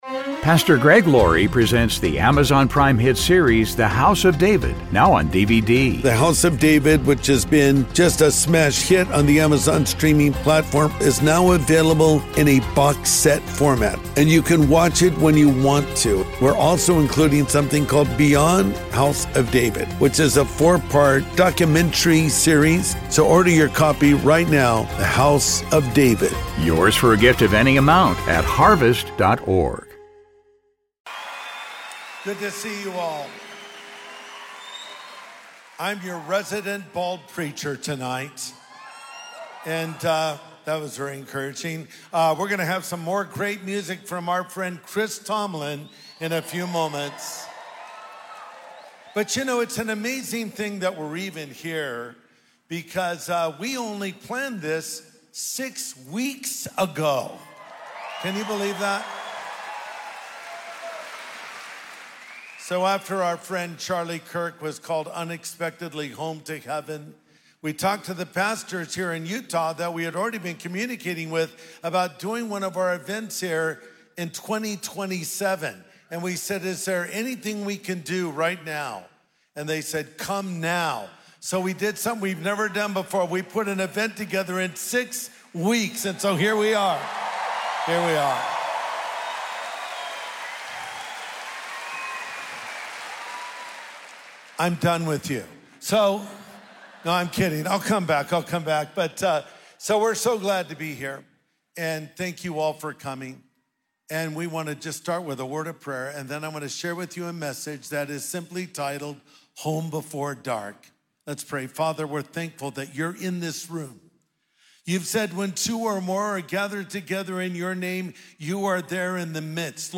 Recorded live November 16 from Utah Valley University
an urgent and heartfelt message